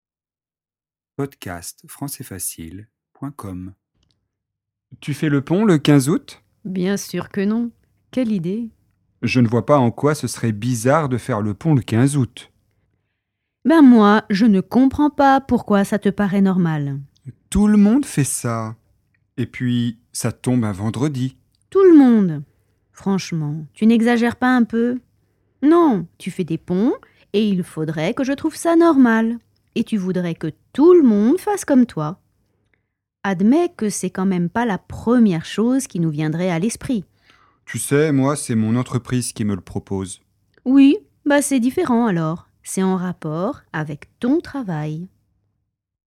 Le pont du 15 août - dialogue FLE